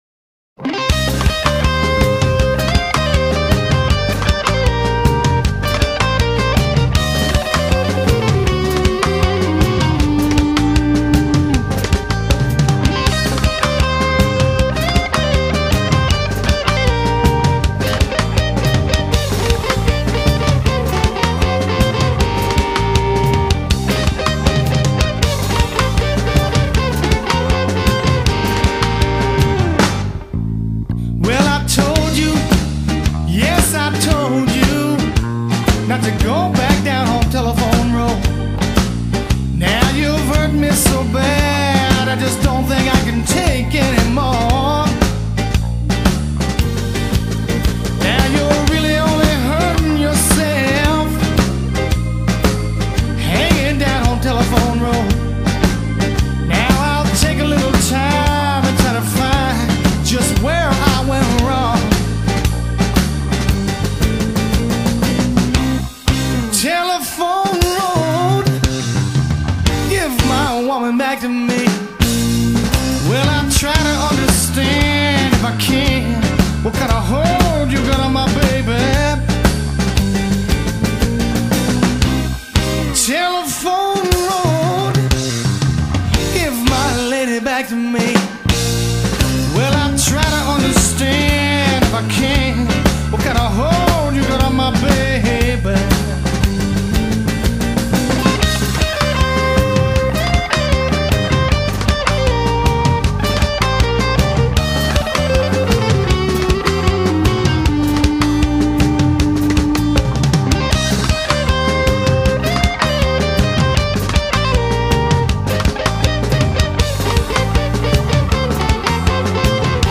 es un gran guitarrista